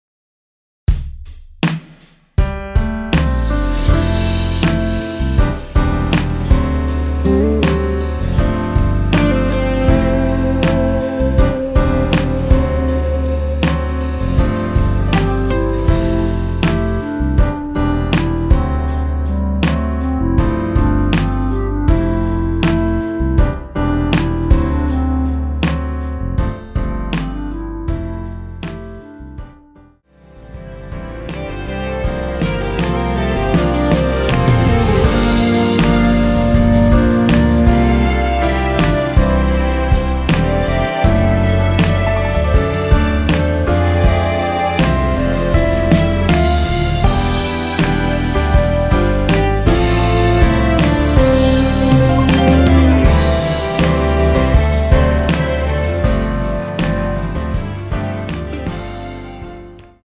원키에서(-4)내린 멜로디 포함된 MR 입니다.(미리듣기 참조)
앞부분30초, 뒷부분30초씩 편집해서 올려 드리고 있습니다.
곡명 옆 (-1)은 반음 내림, (+1)은 반음 올림 입니다.
(멜로디 MR)은 가이드 멜로디가 포함된 MR 입니다.